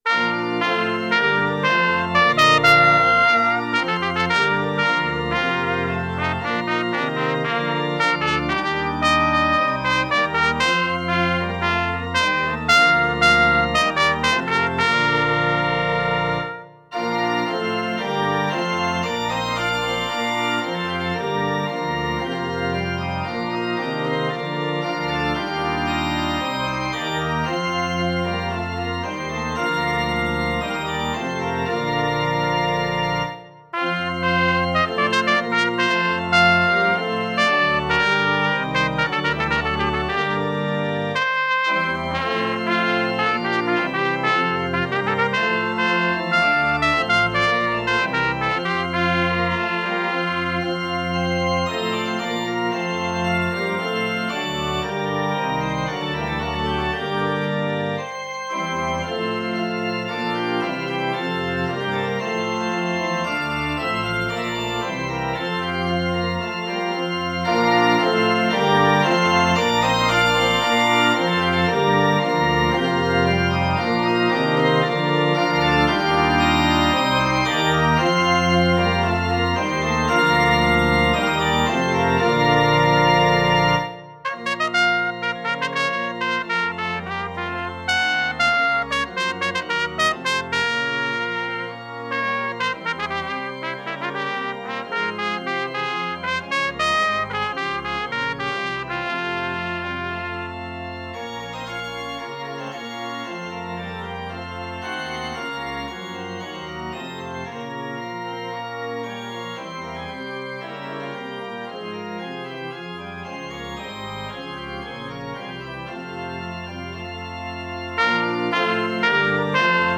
Trompete
Orgel